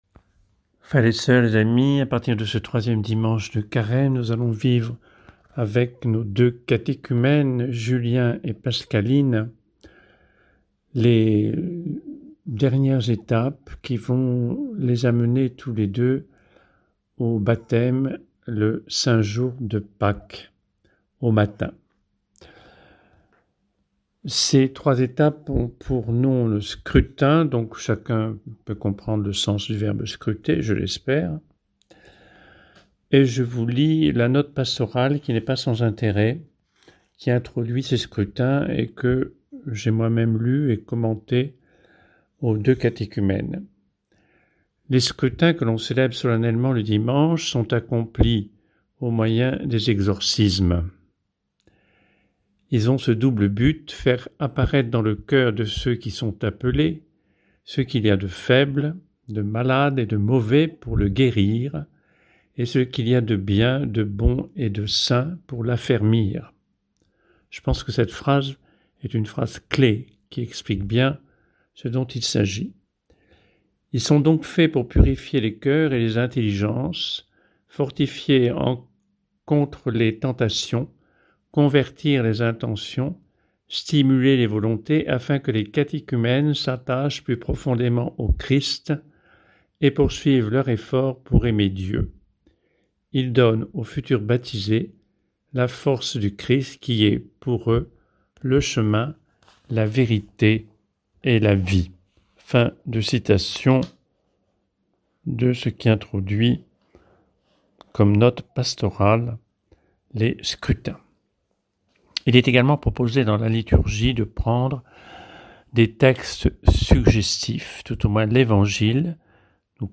Homélie
3e DIMANCHE DE CARÊME